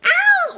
daisy_attacked_3.ogg